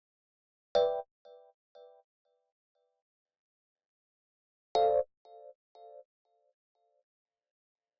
29 ElPiano PT2.wav